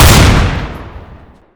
XM1014 ~ 1